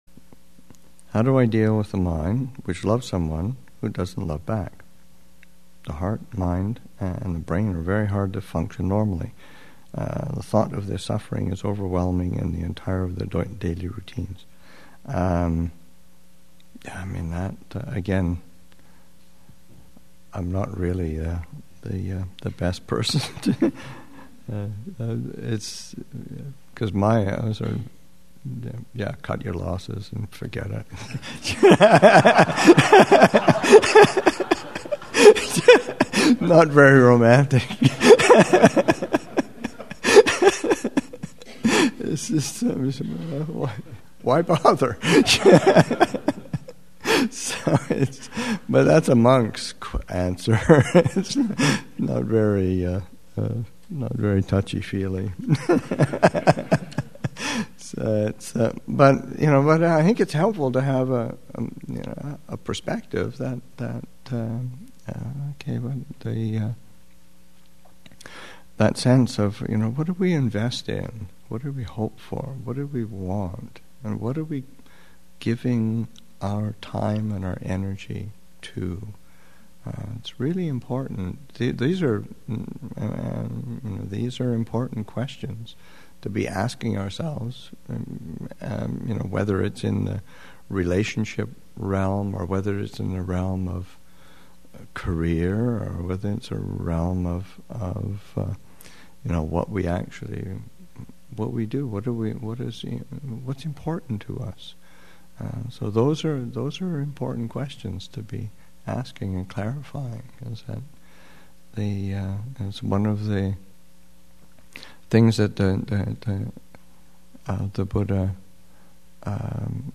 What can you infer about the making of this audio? Thanksgiving Retreat 2016, Session 3 – Nov. 21, 2016